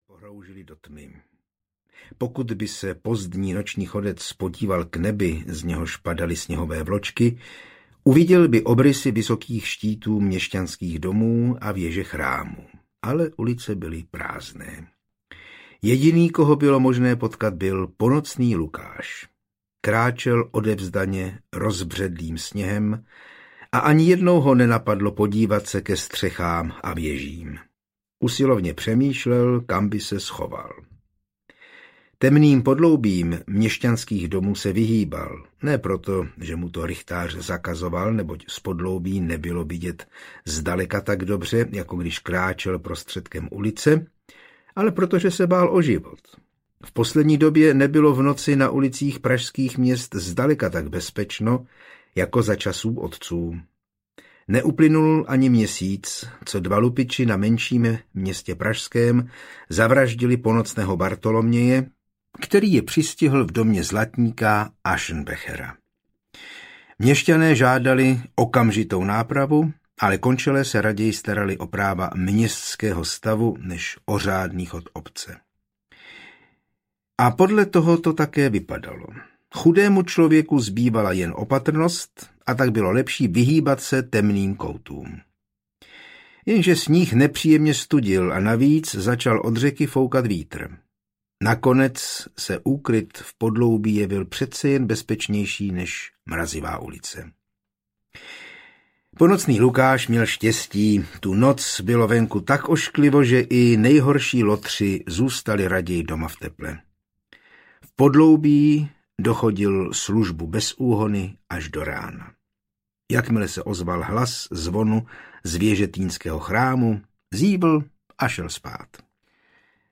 Ukázka z knihy
Audiokniha je bez hudebních předělů a podkresů.